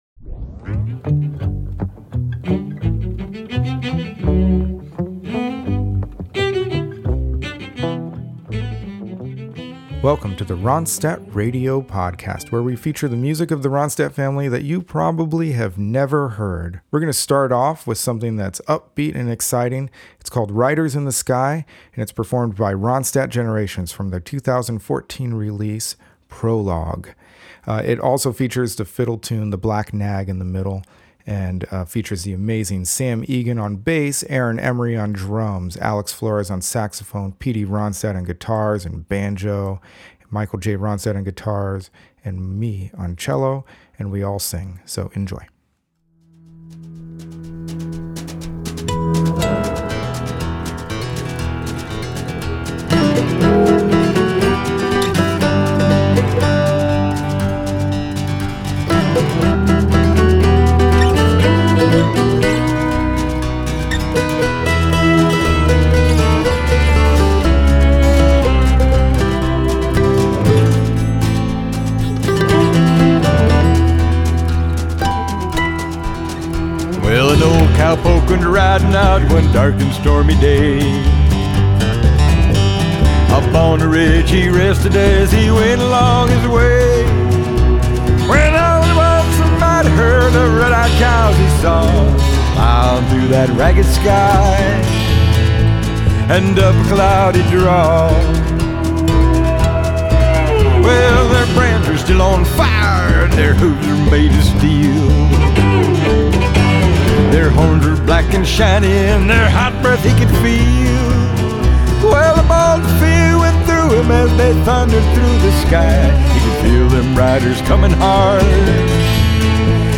which contains a fiddle tune in the middle
percussion
cello